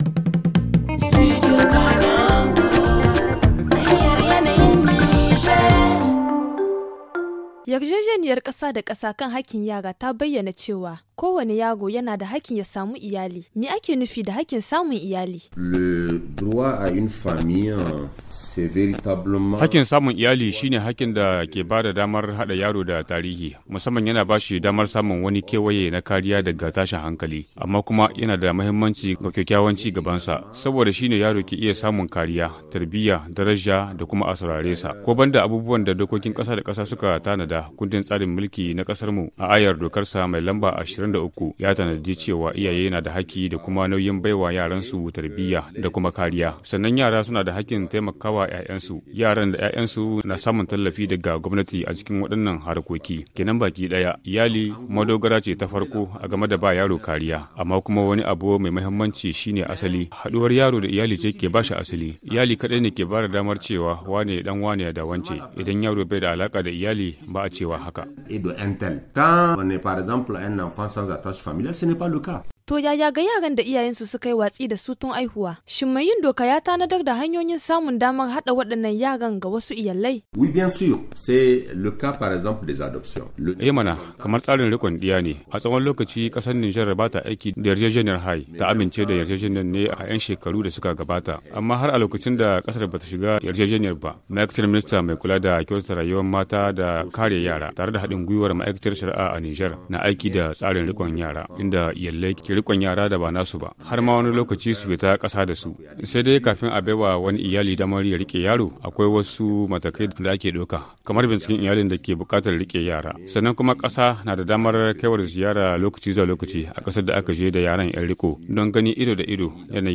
Le magazine en haoussa